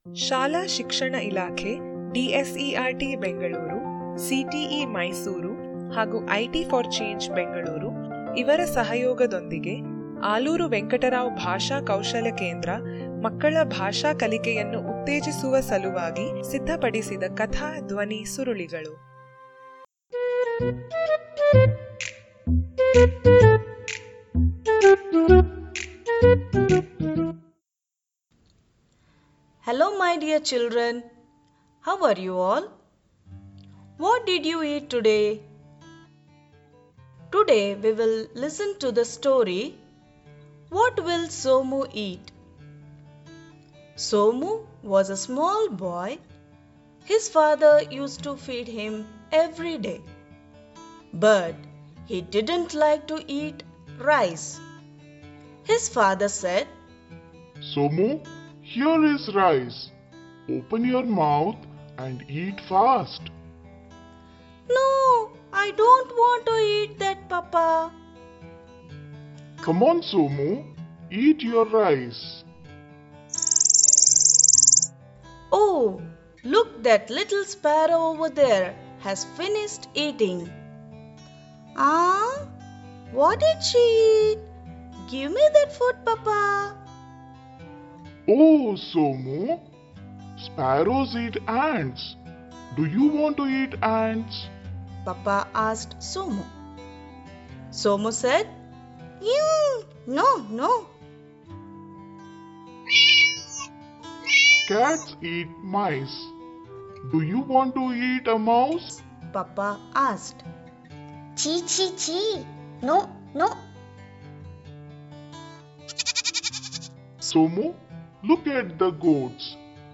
What will somu eat? - Audio Story Activity Page